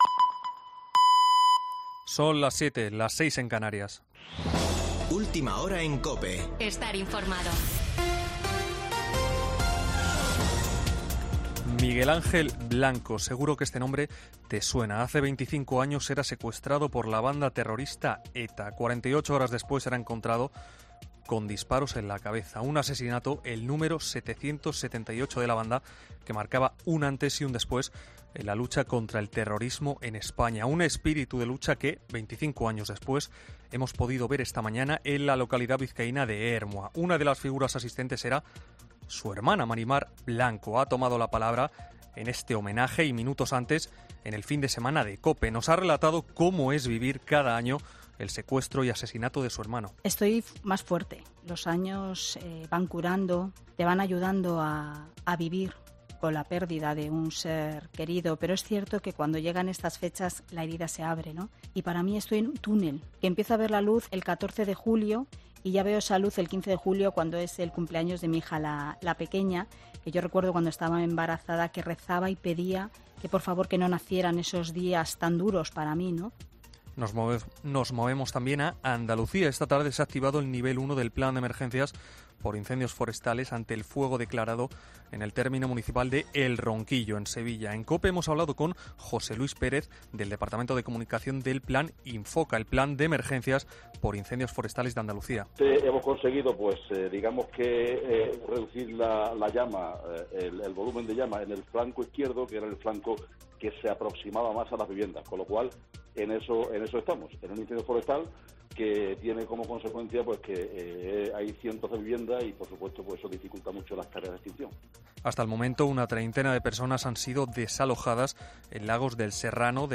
Boletín de noticias de COPE del 10 de julio de 2022 a las 19:00 horas